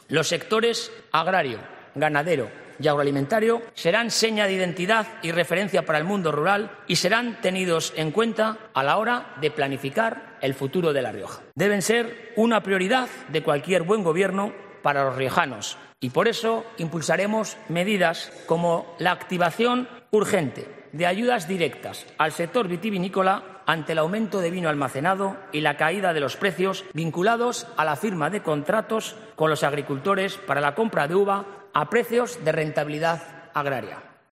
El Parlamento regional ha acogido este mediodía la primera jornada del pleno de investidura del candidato a la presidencia del Gobierno de La Rioja, Gonzalo Capellán.